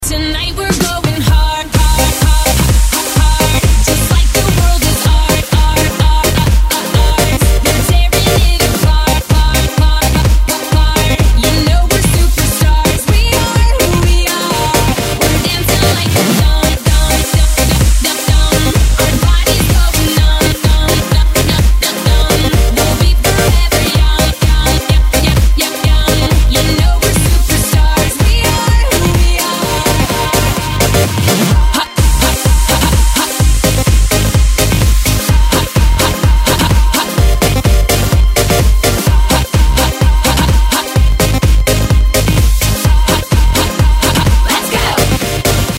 реалтоны pop